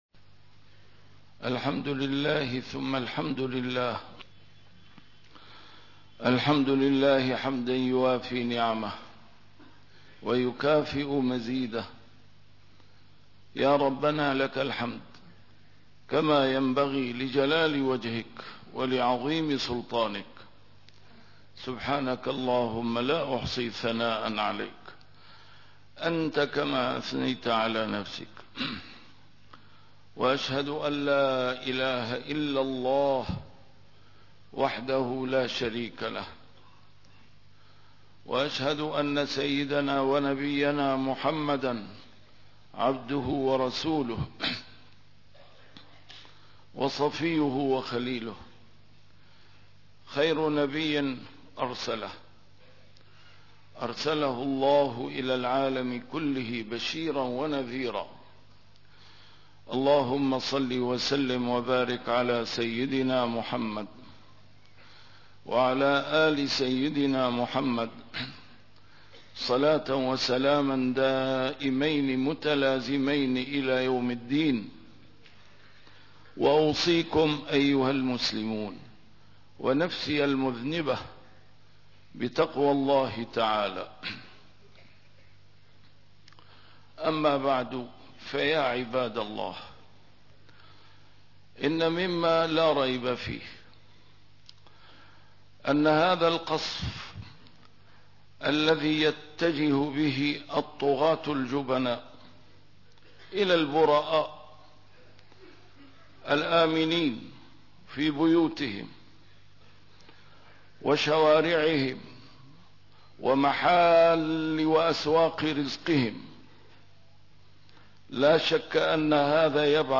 A MARTYR SCHOLAR: IMAM MUHAMMAD SAEED RAMADAN AL-BOUTI - الخطب - العبرة بالعاقبة وليست بمنعرجات الطريق